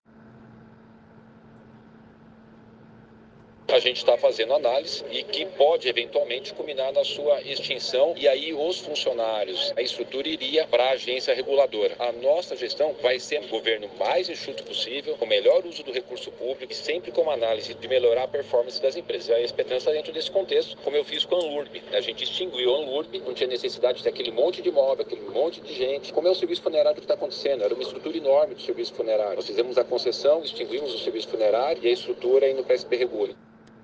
ENTREVISTA – OUÇA: Ricardo Nunes confirma nesta segunda (11) em viva-voz que SPTrans pode ser extinta e segue mesma linha de Tarcísio em relação à EMTU
Em agenda na região de São Mateus, zona Leste da capital paulista, na manhã desta segunda-feira, 11 de novembro de 2024, dentro do programa “Prefeitura Presente”, o prefeito de São Paulo, Ricardo Nunes, confirmou que estão em andamento as análises que podem culminar na extinção da SPTrans (São Paulo Transporte), que gerencia o sistema de ônibus da capital paulista, o Bilhete Único e os serviços de mobilidade na cidade.